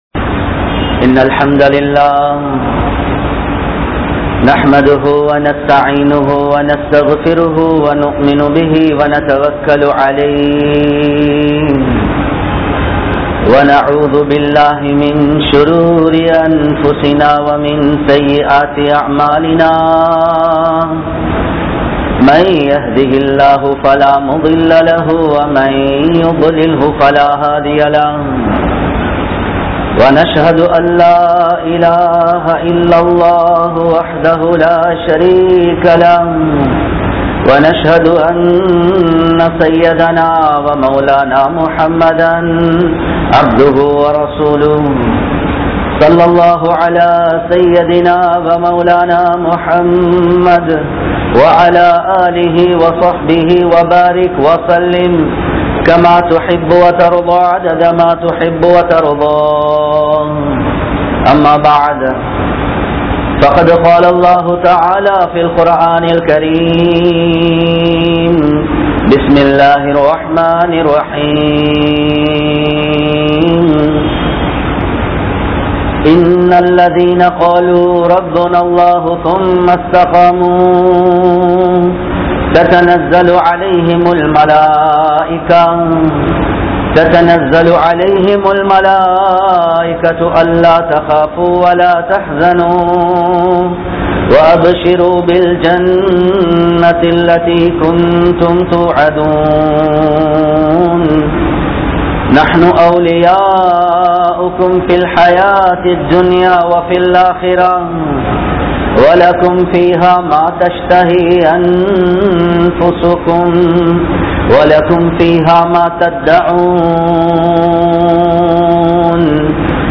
Manithan Padaikkap pattathan Noakkam (மனிதன் படைக்கப்பட்டதன் நோக்கம்) | Audio Bayans | All Ceylon Muslim Youth Community | Addalaichenai
Kollupitty Jumua Masjith